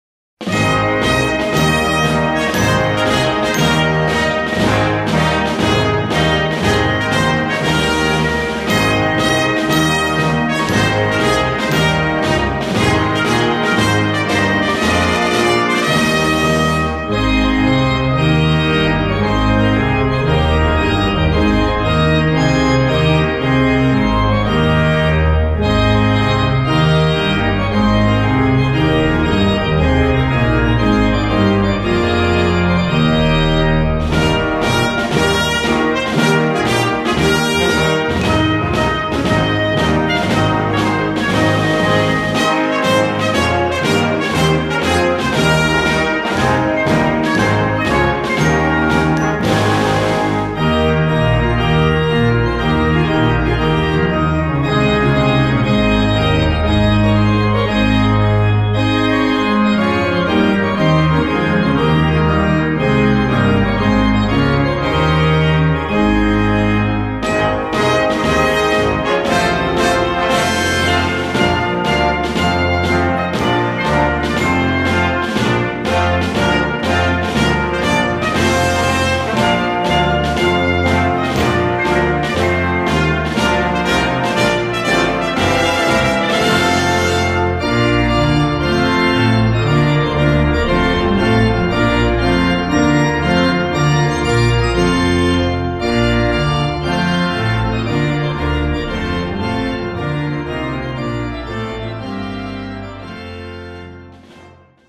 Solo für Orgel und Blasorchester
Besetzung: Blasorchester